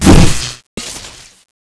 midslash2_hit.wav